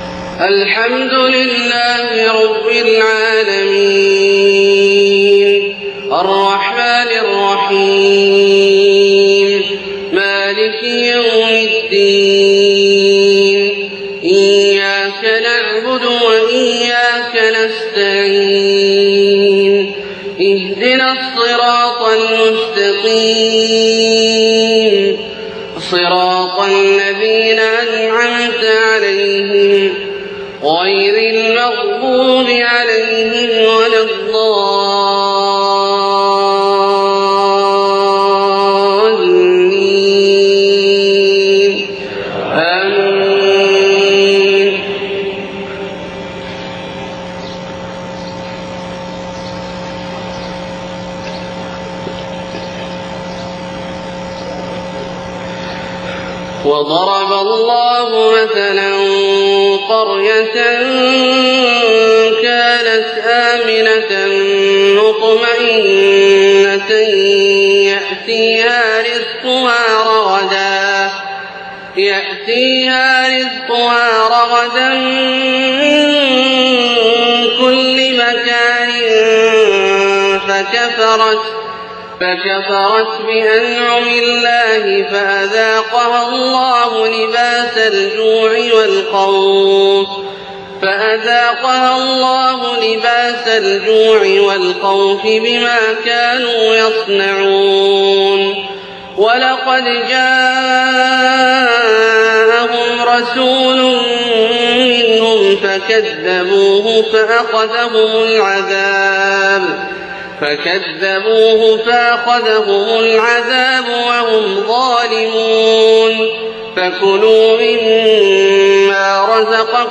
صلاة الفجر 15صفر 1430هـ خواتيم سورة النحل 112-128 > 1430 🕋 > الفروض - تلاوات الحرمين